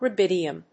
音節ru・bid・i・um 発音記号・読み方
/ruːbídiəm(米国英語)/